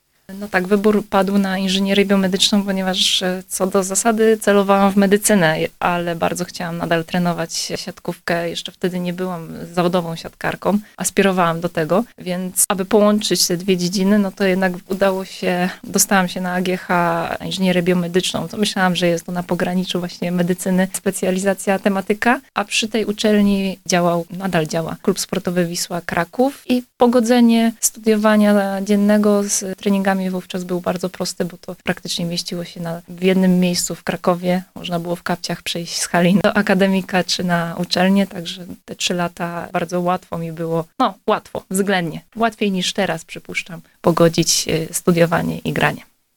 Cała rozmowa